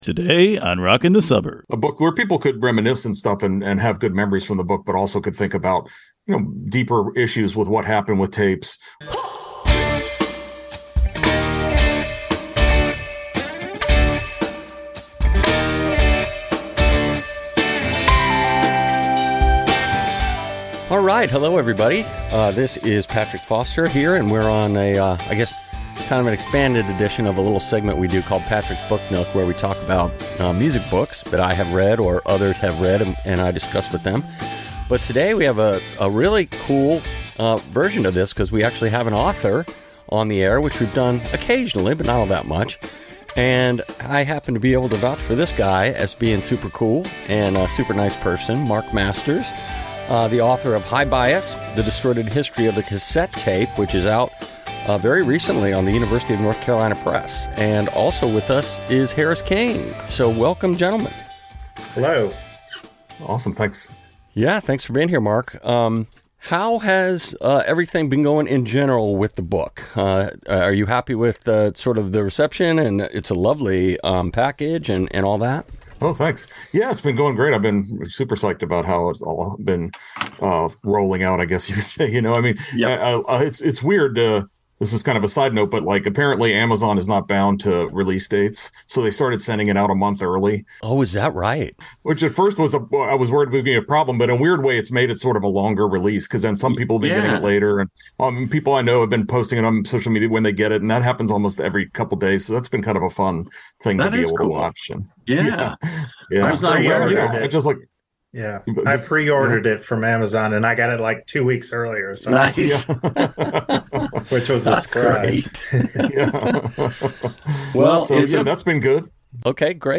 Book Nook Interview Part 1
This is the first of two-part interview.